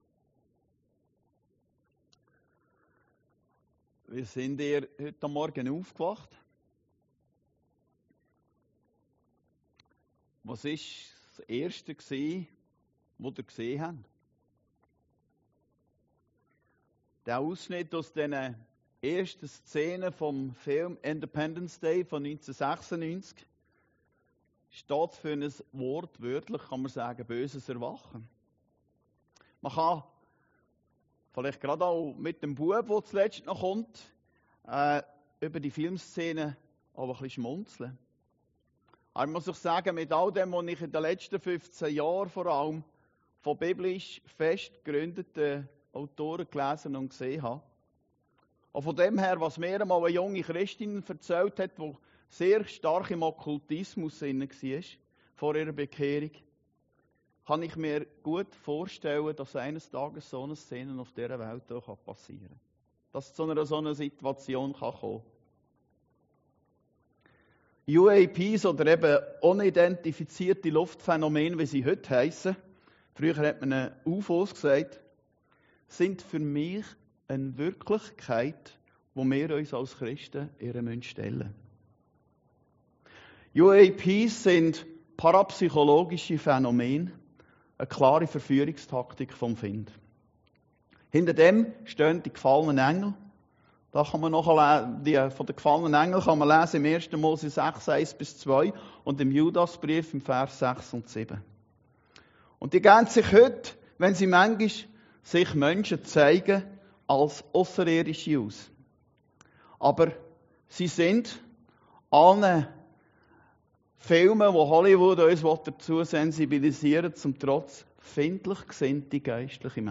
Predigten Heilsarmee Aargau Süd – Augenöffner-Geschichten 2